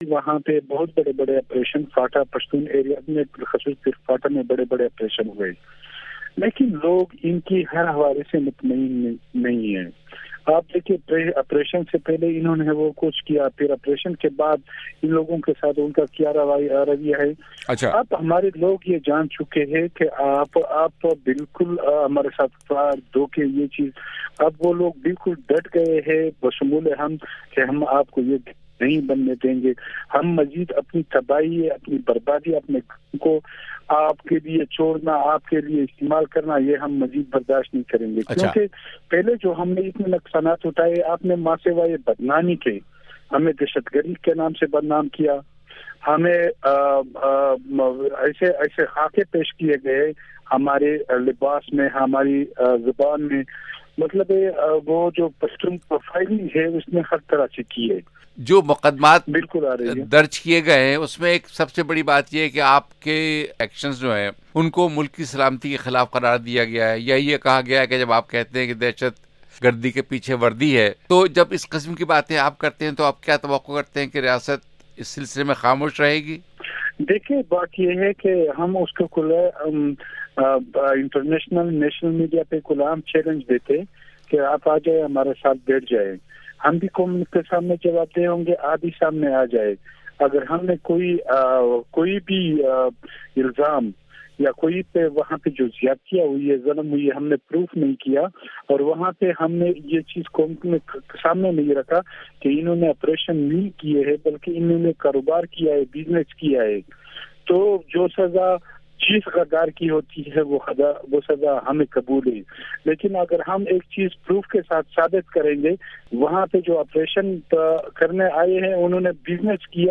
علی وزیر، رہنما پی ٹی ایم
صوبائی وزیر اطلاعات شوکت یوسفزئی